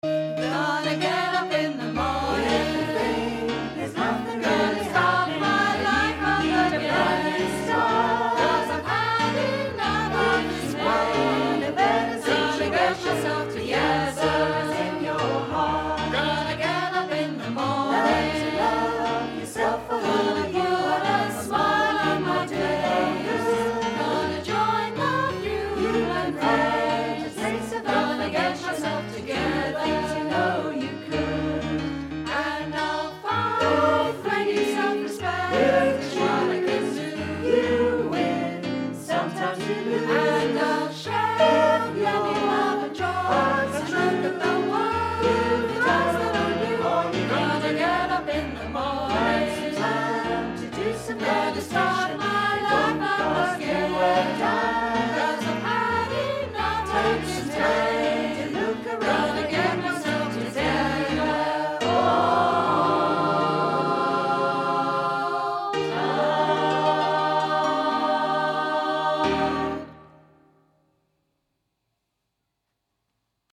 calm and riotous, exultant and tranquil